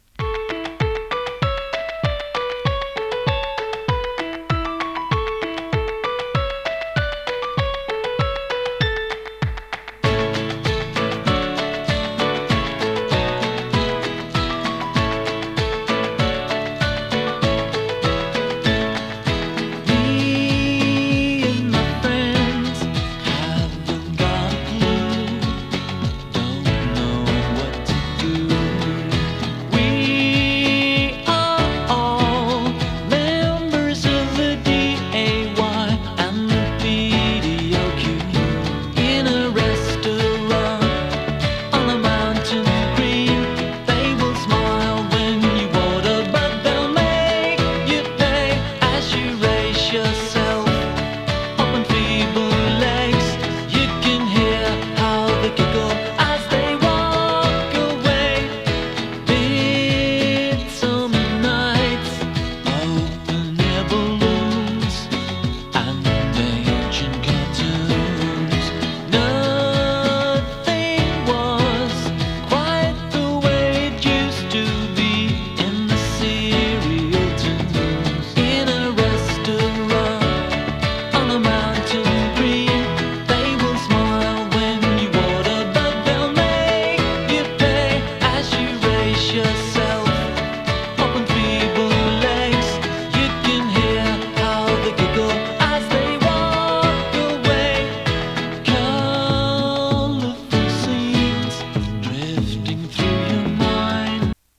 シンセポップ
心地よい浮遊感と温もりを感じさせるエレポップの傑作です！